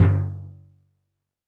Drums_K4(44).wav